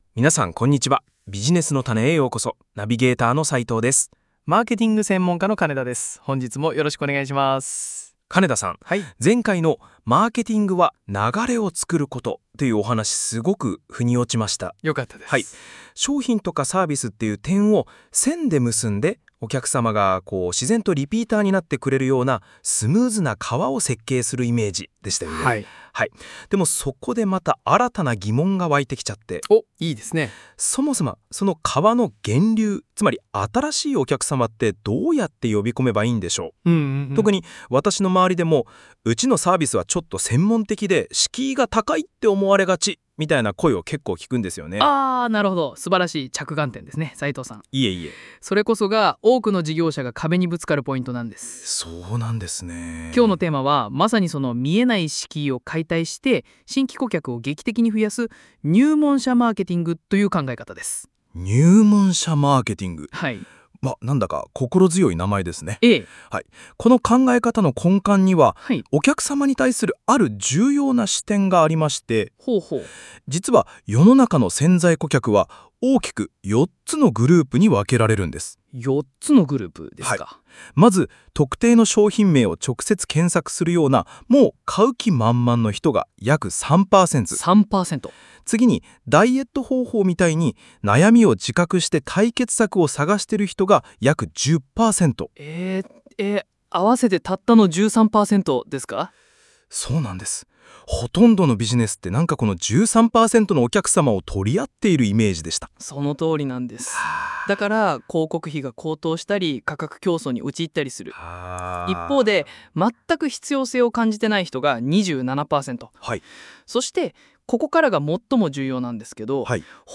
会話の文字起こしはこちら↓